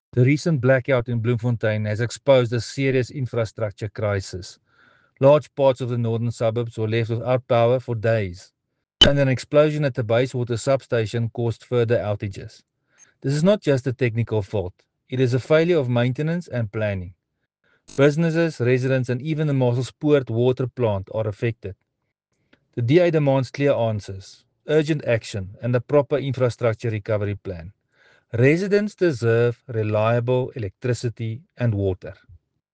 Afrikaans soundbites by Cllr Rudi Maartens and